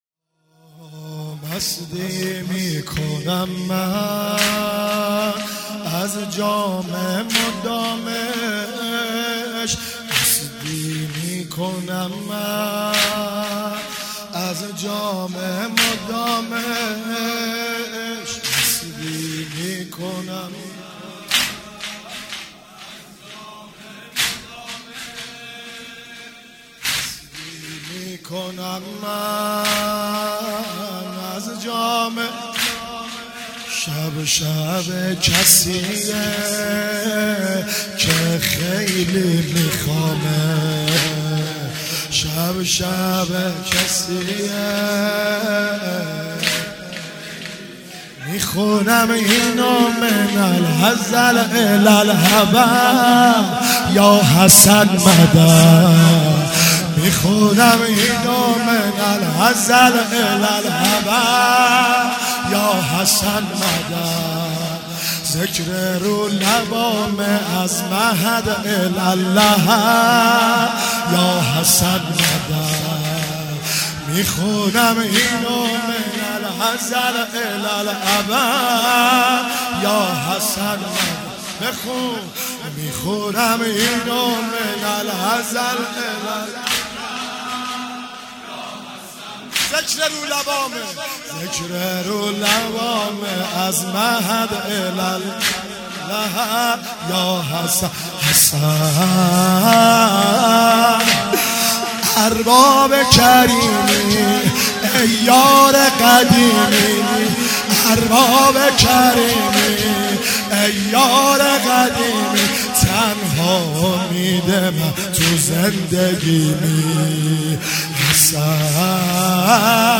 محرم95